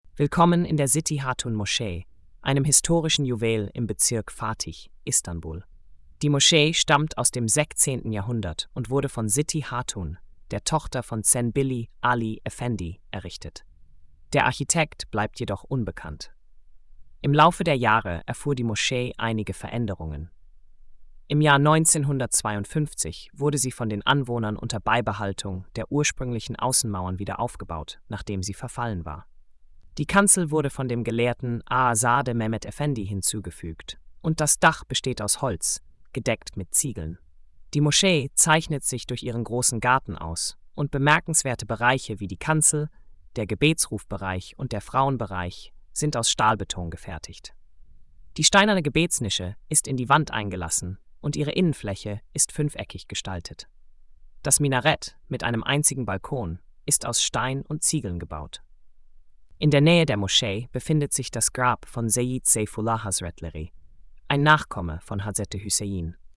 Audio Erzählung